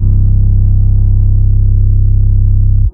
808 (Dior).wav